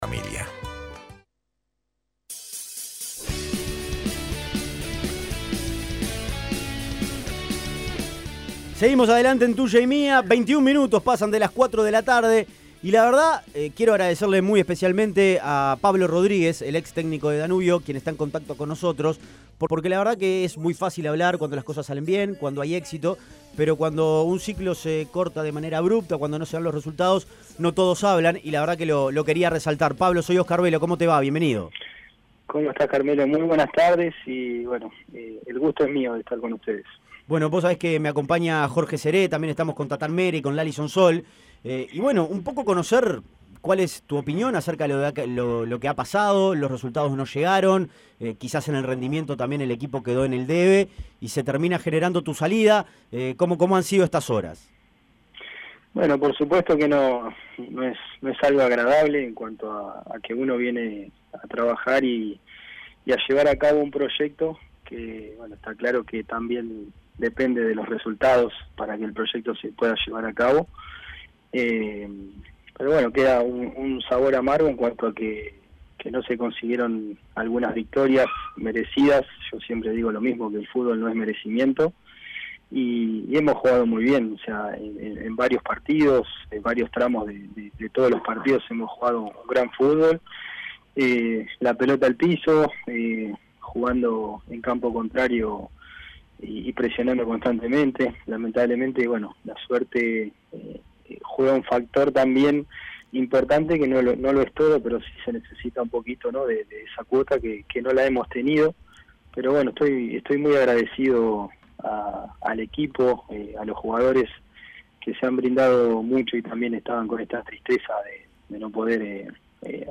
Analizó las causas, desmintió rumores y se mostró agradecido con mucha gente de "la franja" por el trato recibido. Entrevista completa.